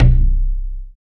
20 LOG DRM-L.wav